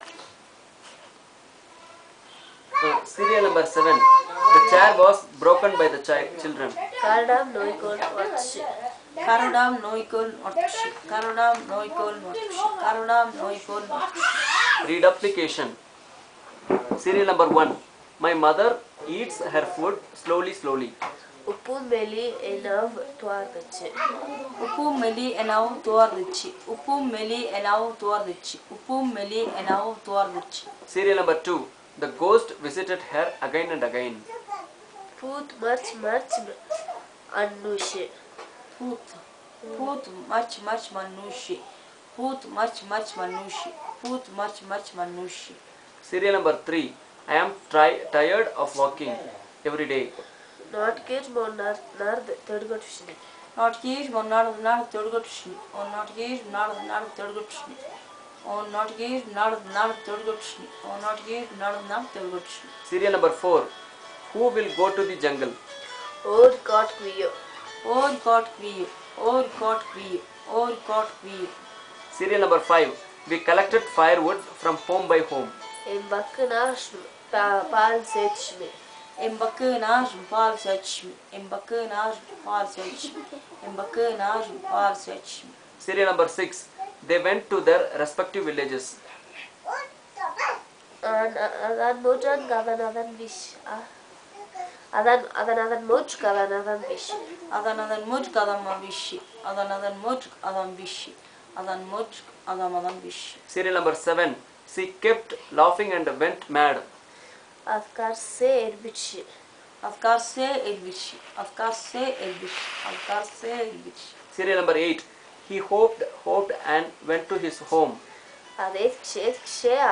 NotesThis is an elicitation of sentences with reduplicated forms, echo-formations, and inflectional categories associated with these, using the SPPEL Language Documentation Handbook.